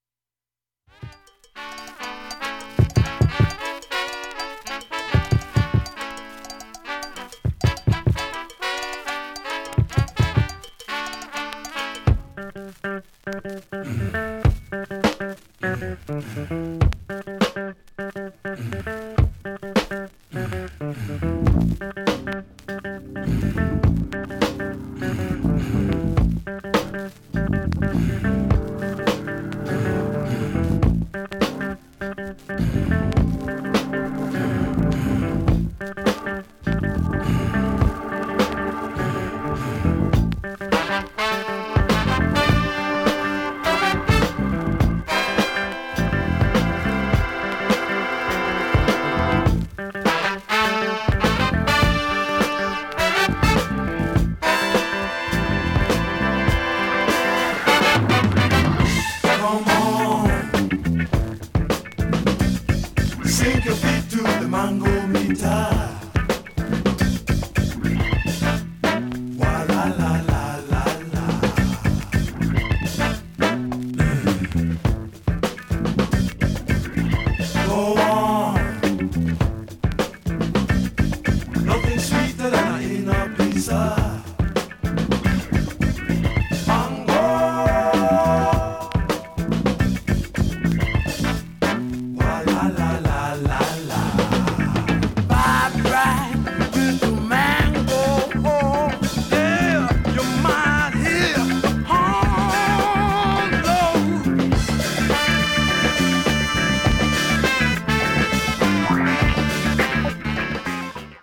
ネタのレアグルーヴ・クラシック